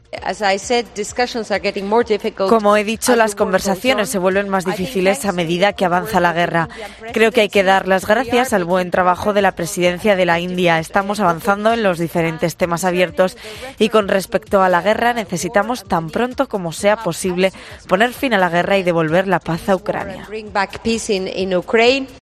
Nadia Calviño en la reunión de ministros de Finanzas del G20: "Necesitamos devolver la paz a Ucrania"
"Las conversaciones para un acuerdo común del G20 se están haciendo más difíciles que en las reuniones previas porque mientras continúa la guerra algunas de estas posiciones están siendo quizá menos constructivas en estos temas", ha manifestado en unen una rueda de prensa en el contexto de la reunión de ministros de Finanzas del G20 en Bangalore (India)